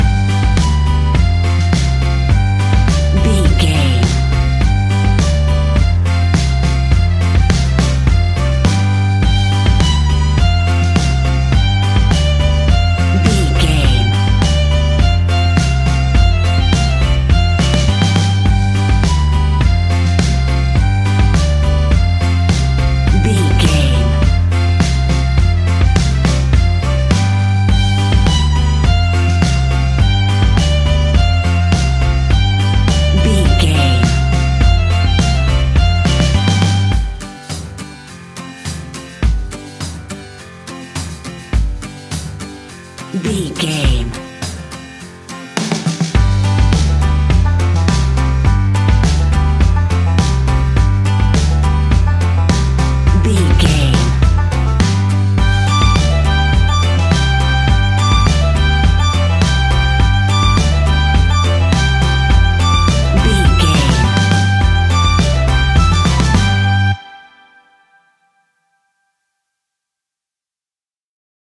Uplifting
Ionian/Major
acoustic guitar
mandolin
ukulele
lapsteel
drums
double bass
accordion